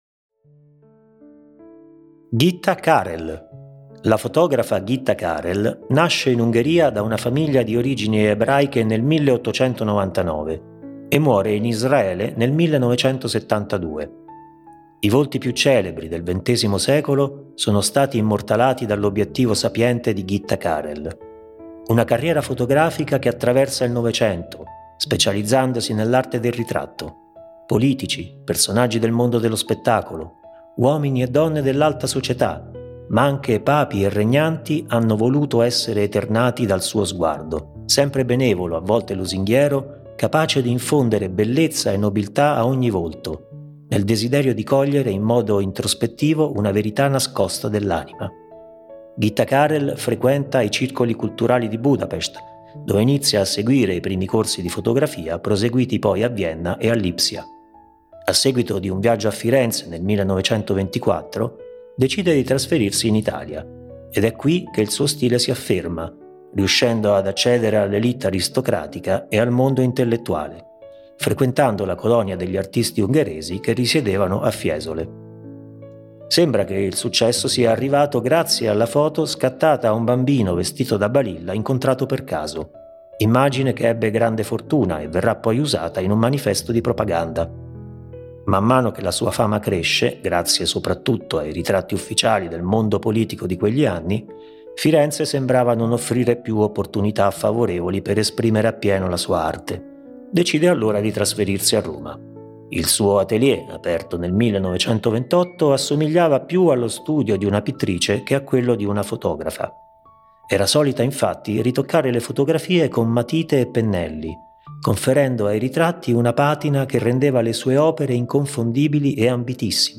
AUDIOGUIDA
Registrazioni effettuate presso Technotown